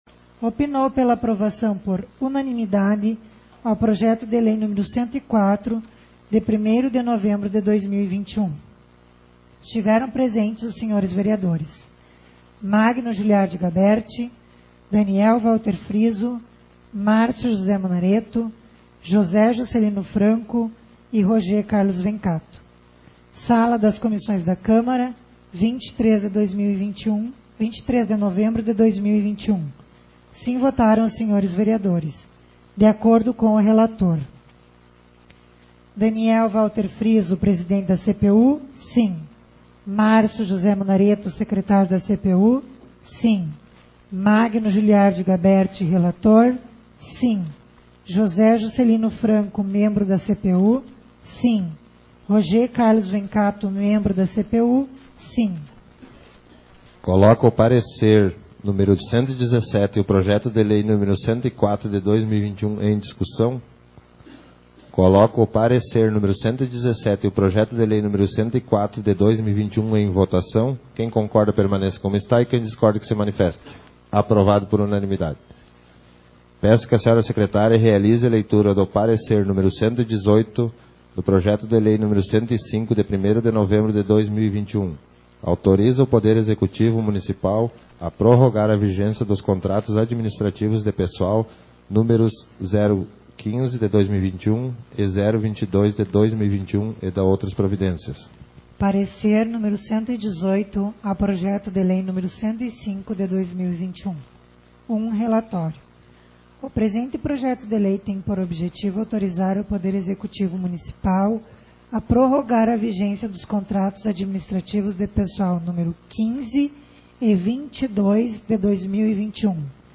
Sessões Plenárias